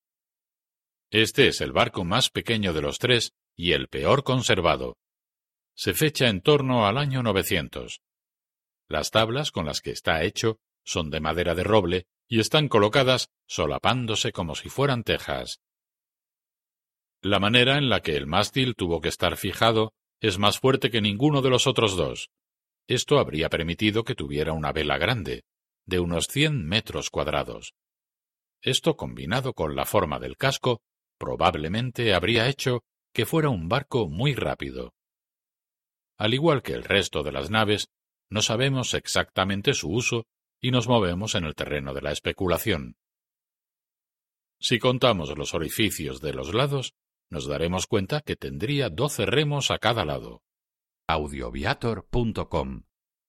audioguía_Museo_de_los_Barcos_Vikingos_Oslo_ES_10.mp3